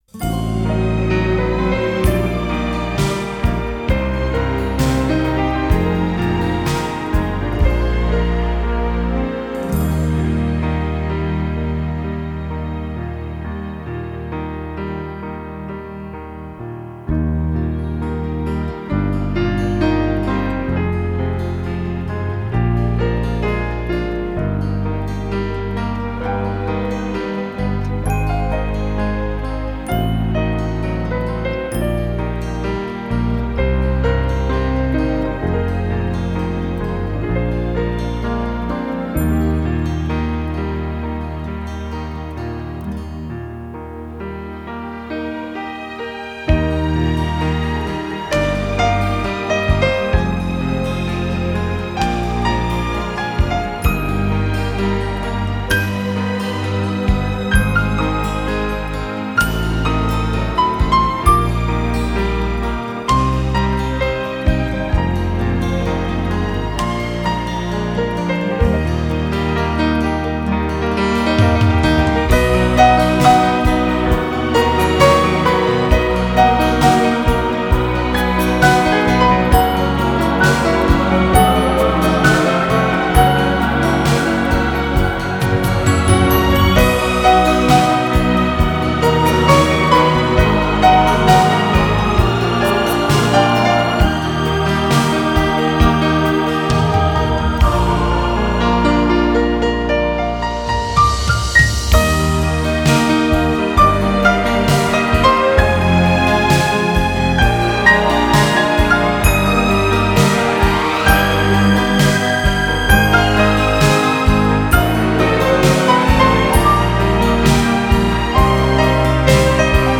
меняем сакс на пиано